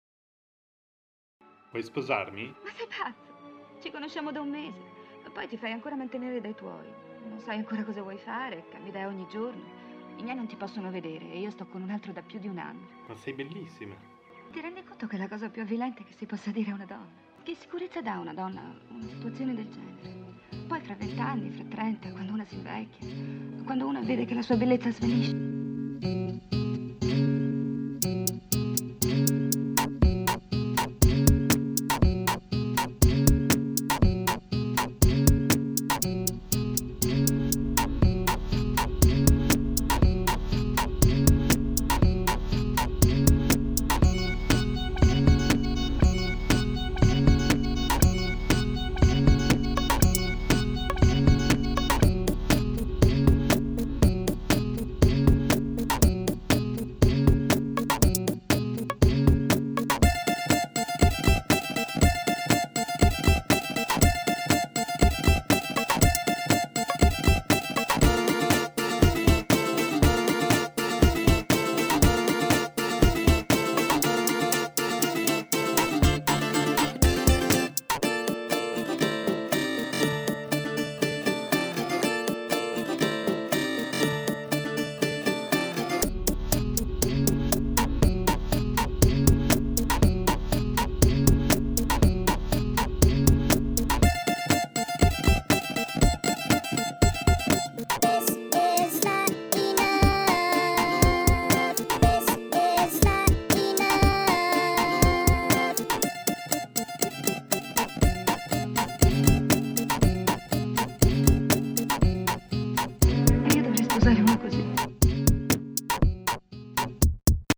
vocalist